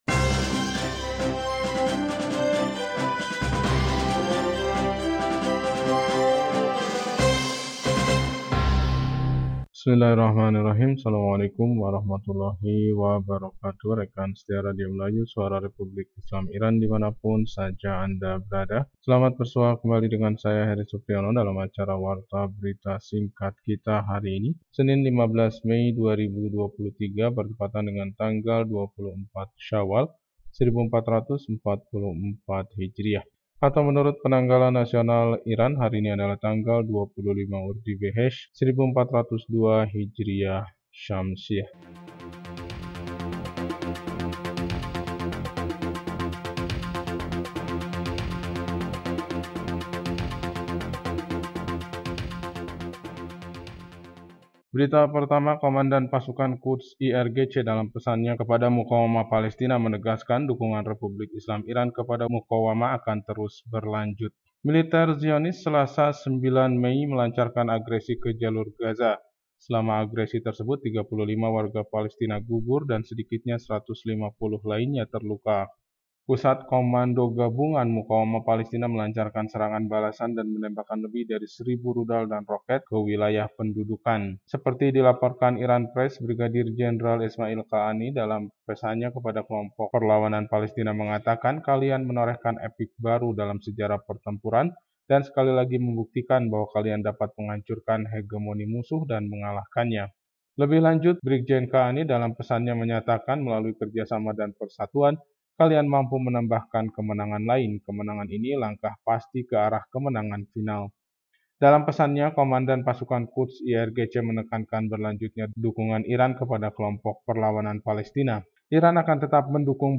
Warta Berita 15 Mei 2023